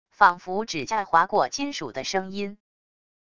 仿佛指甲划过金属的声音wav音频